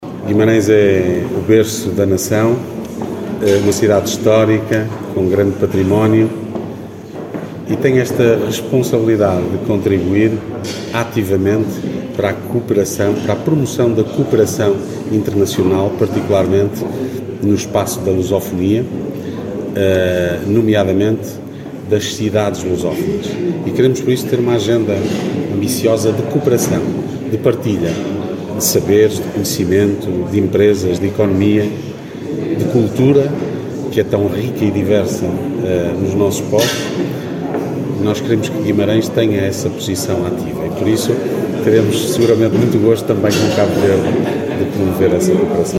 Declarações de Ricardo Araújo, presidente da Câmara Municipal. Falava ontem aquando da visita do Presidente da República de Cabo Verde, José Maria Neves, a Guimarães.